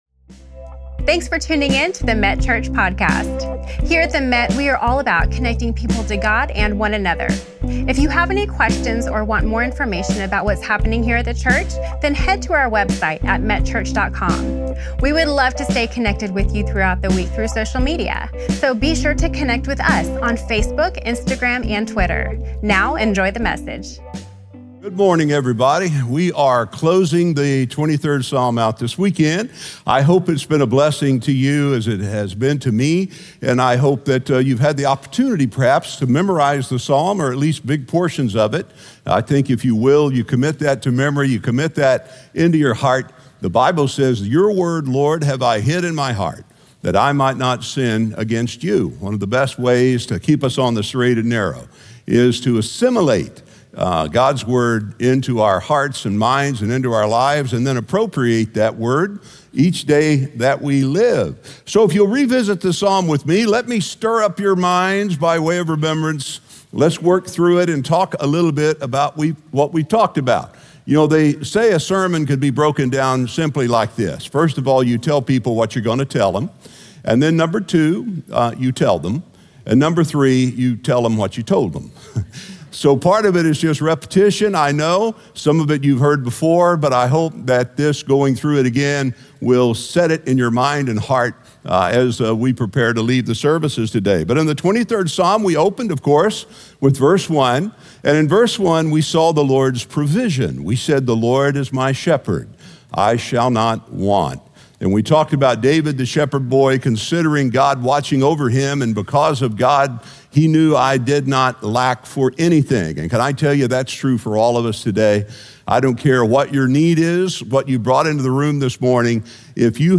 Senior Pastor